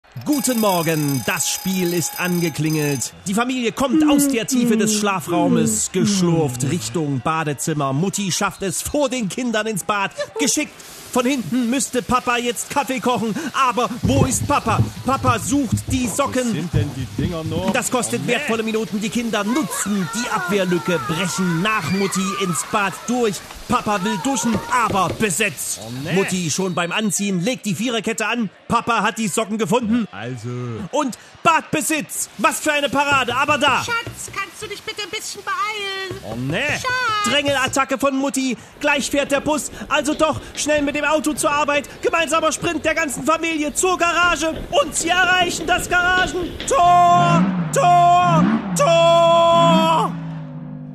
Er kann nicht anders: Er muss alles was morgens in Deiner Familie passiert kommentrieren: Als Fußballspiel!